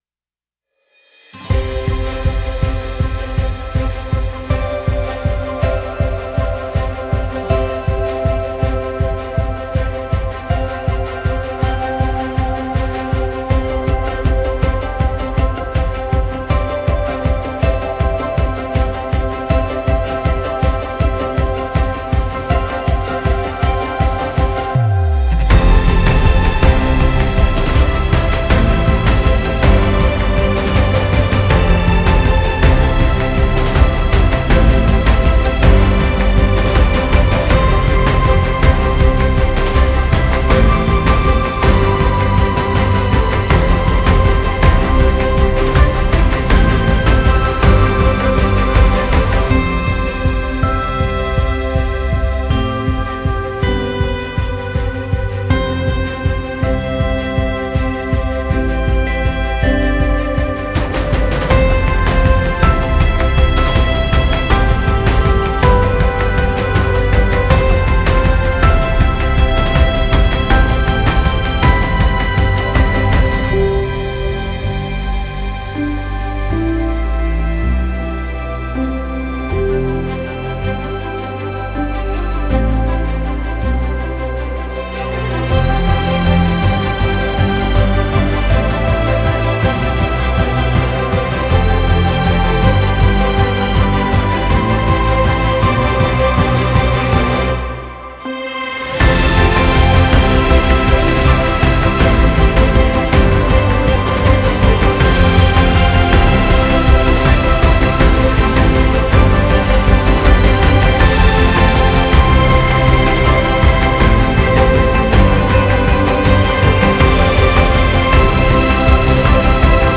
BPM80-160
Audio QualityPerfect (Low Quality)